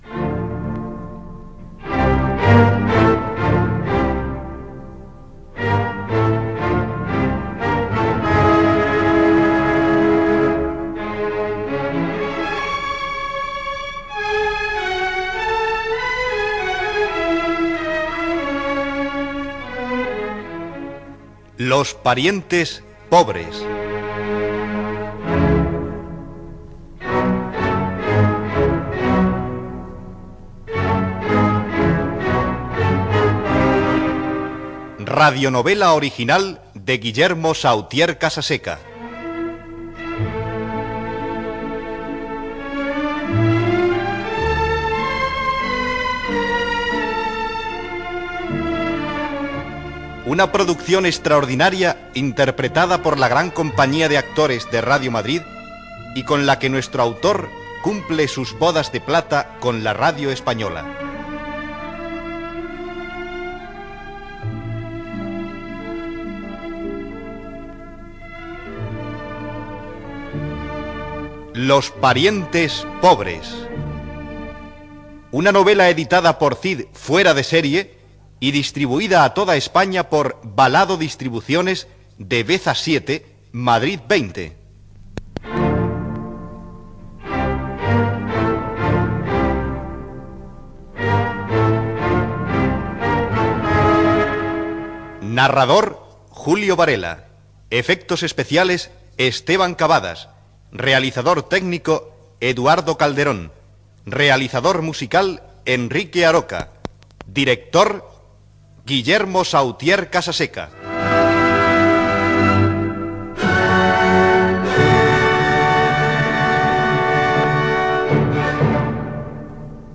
Careta d'entrada Gènere radiofònic Ficció